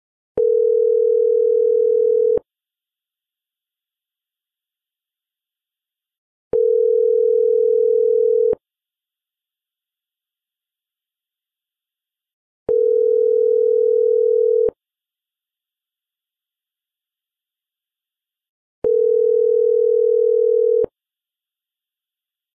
Звуки Viber
В подборке — короткие и узнаваемые сигналы, которые помогут настроить мессенджер под ваш стиль.